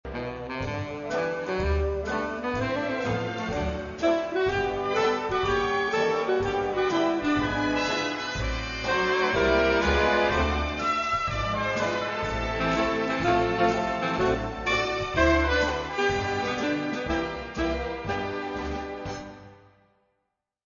which he uses a wistful
promenade theme to represent the Tramp and